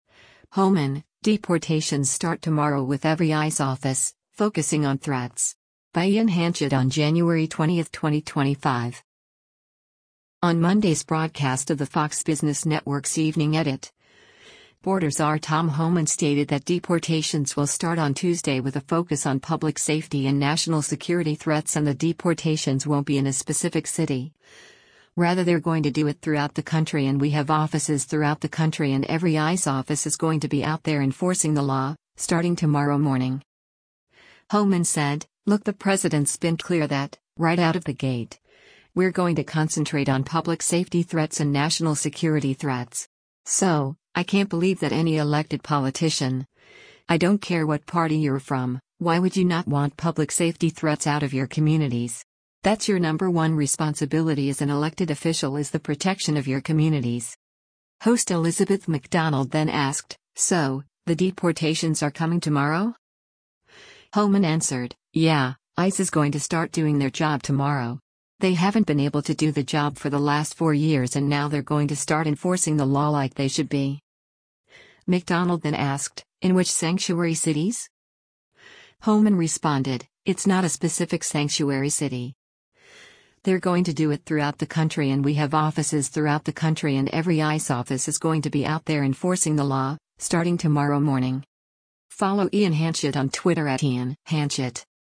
On Monday’s broadcast of the Fox Business Network’s “Evening Edit,” Border Czar Tom Homan stated that deportations will start on Tuesday with a focus on public safety and national security threats and the deportations won’t be in a specific city, rather “They’re going to do it throughout the country and we have offices throughout the country and every ICE office is going to be out there enforcing the law, starting tomorrow morning.”
Host Elizabeth MacDonald then asked, “So, the deportations are coming tomorrow?”